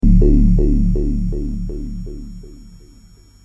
SONS ET SAMPLES DU SYNTHÉTISEUR OBERHEIM MATRIX 1000